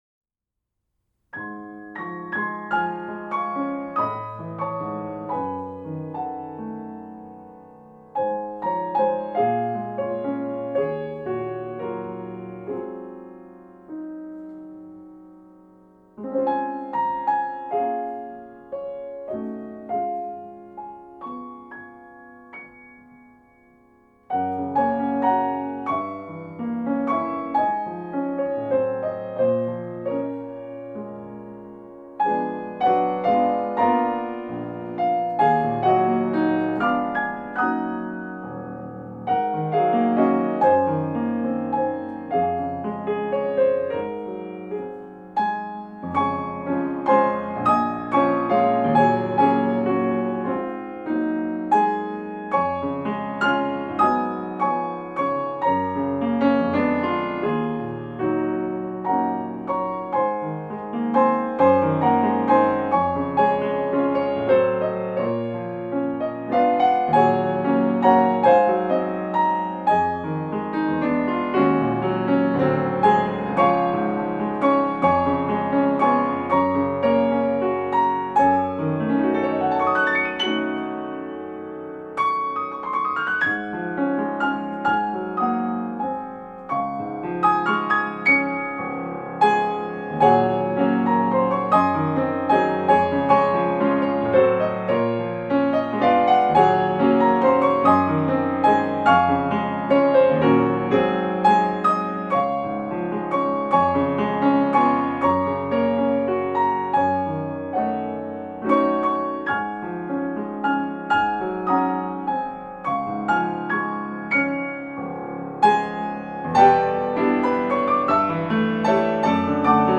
A richly harmonic piano solo setting of the hymn